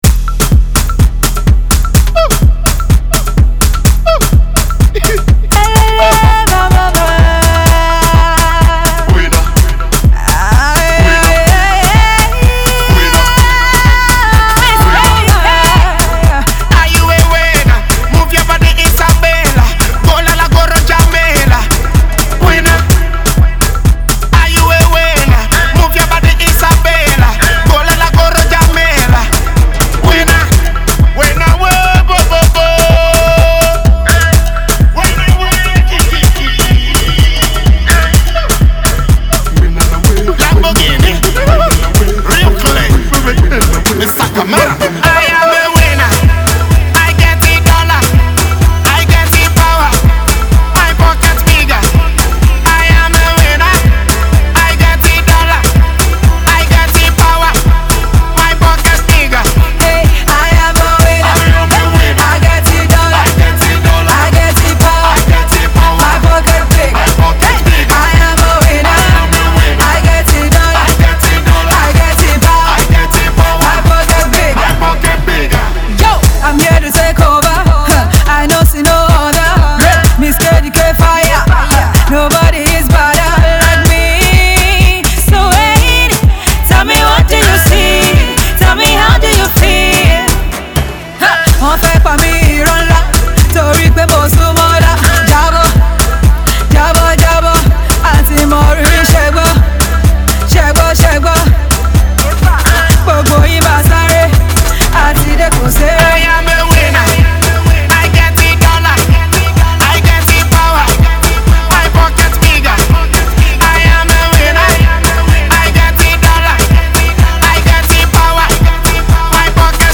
AudioDancehall